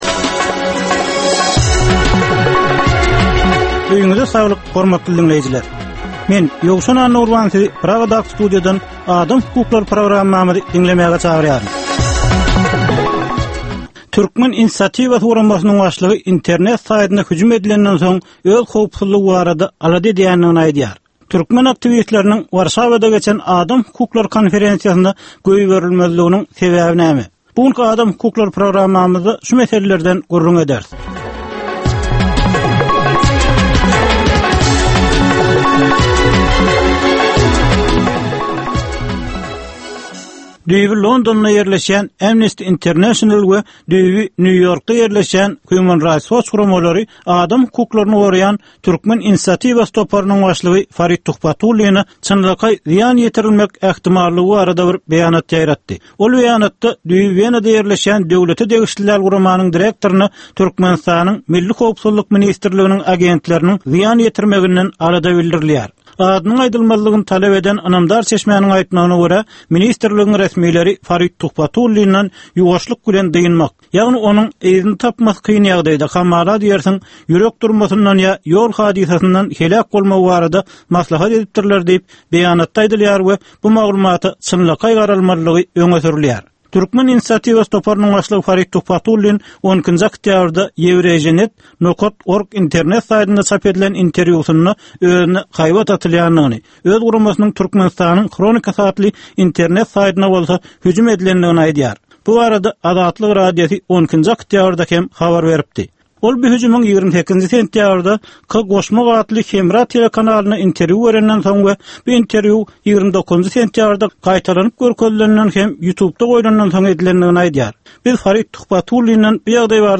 Türkmenistandaky adam hukuklarynyň meseleleri barada ýörite programma. Bu programmada adam hukuklary bilen baglanyşykly anyk meselelere, problemalara, hadysalara we wakalara syn berilýär, söhbetdeşlikler we diskussiýalar gurnalýar.